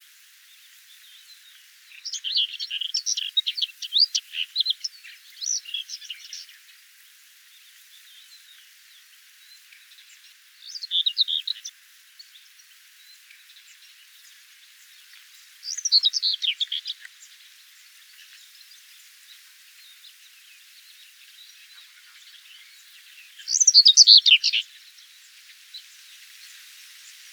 - COMMENT: this is a rare case of Whitethroat found in the lowland of Maremma. The first song phrase is long and not loud, maybe it is a subsong. The following phrases are typical for the species. The recording was interrupted for 40 s (see spectrograms).